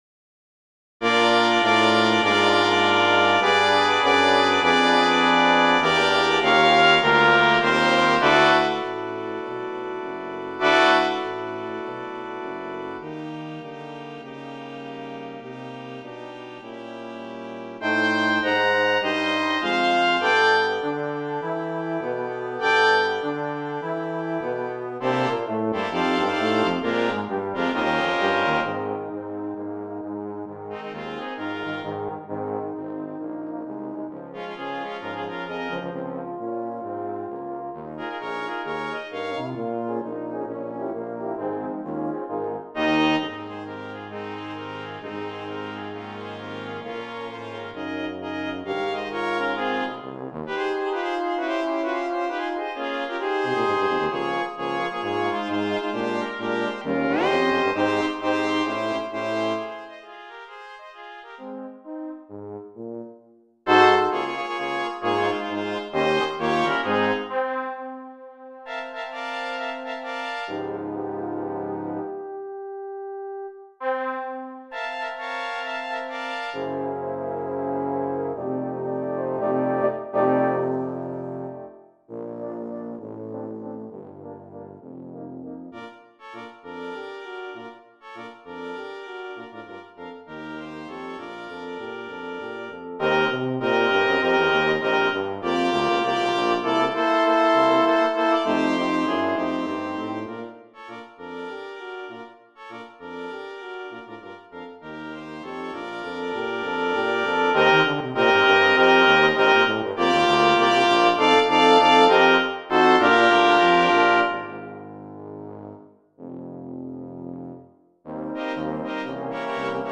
Voicing: Double Brass Quintet